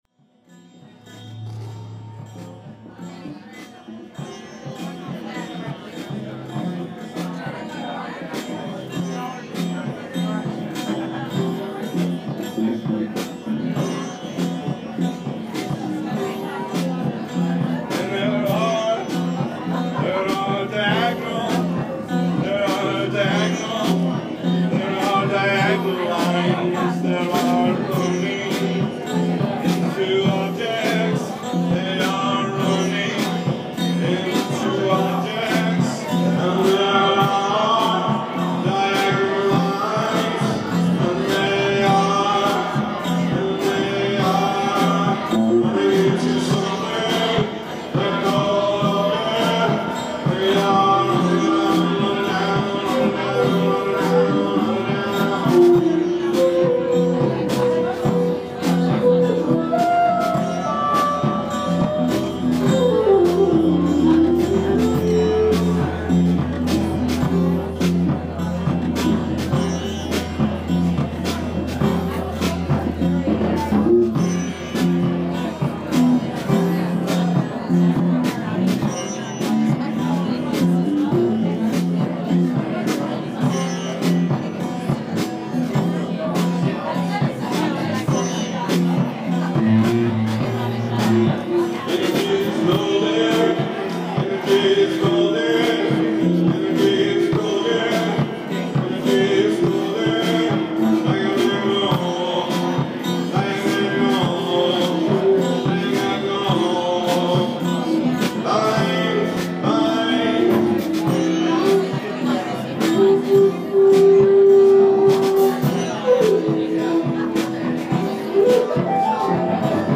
OHR Jaus Gallery, Sep 16th, 2016
ALL MUSIC IS IMPROVISED ON SITE